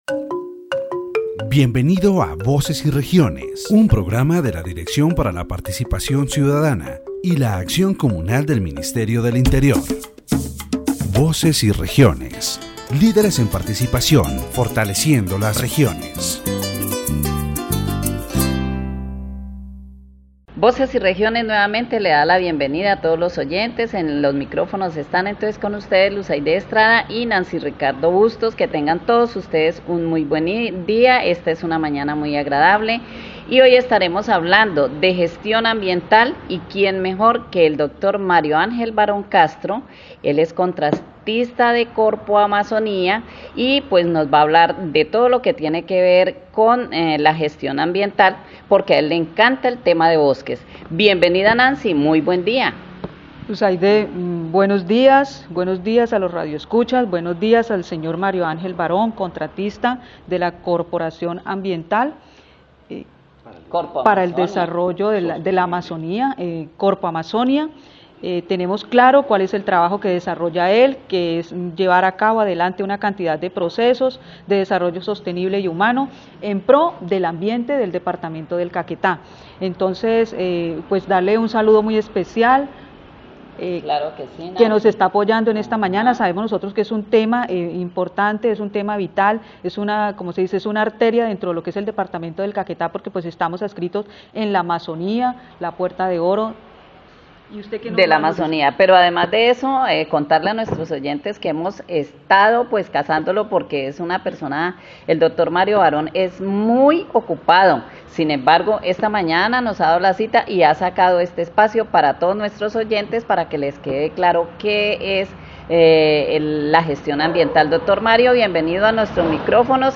The radio program "Voices and Regions" of the Directorate for Citizen Participation and Communal Action of the Ministry of the Interior focuses on environmental management in the Department of Caquetá.